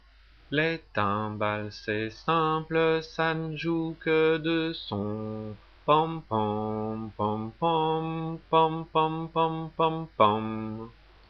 Téléchargement: les timbales (MP3, 155.4 kio)
timbales.mp3